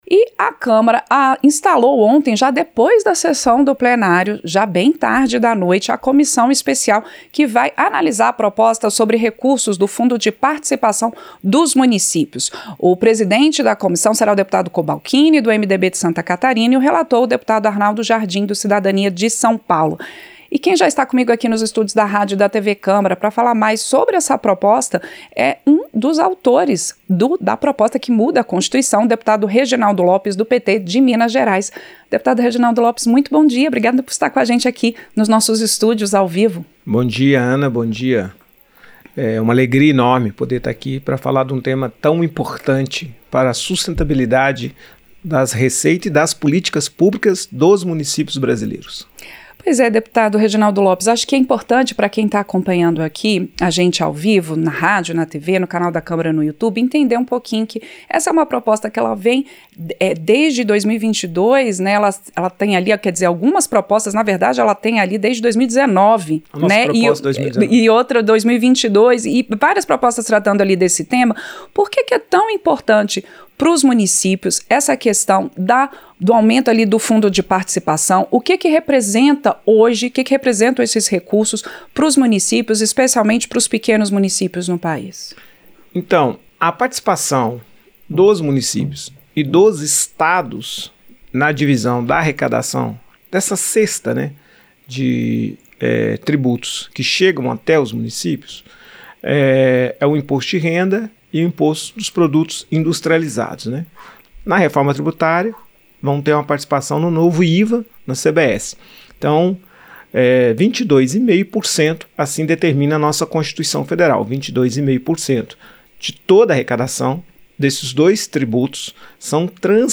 Entrevista - Dep. Reginaldo Lopes (PT-MG)